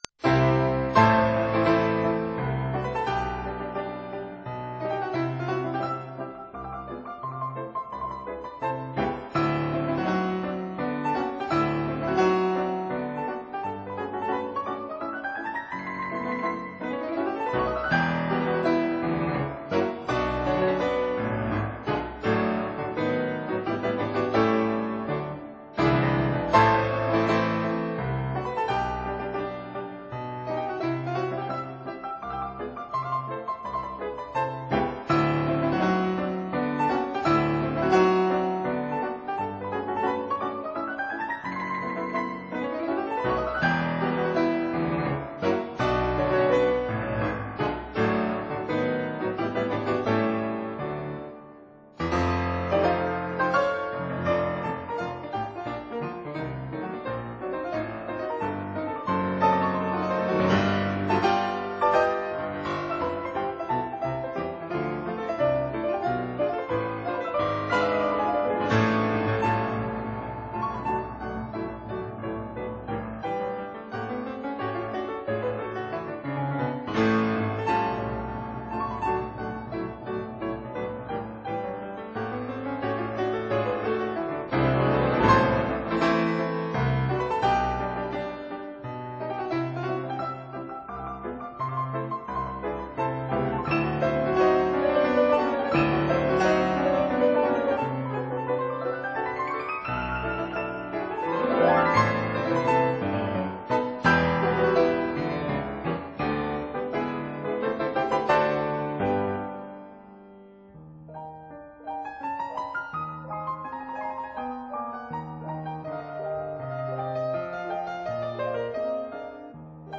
pianos